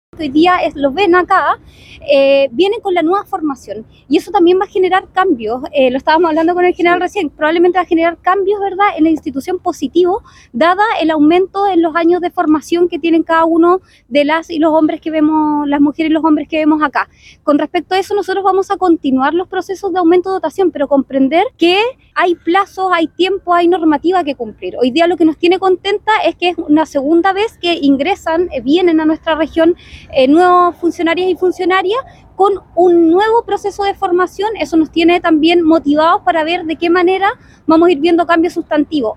El nuevo contingente fue recibido por la delegada presidencial de la Región de Los Lagos, Giovanna Moreira, quien destacó que los funcionarios y funcionarias vienen con la nueva formación y eso va a generar cambios positivos en la institución, dado el aumento en los años de formación de los hombres y mujeres.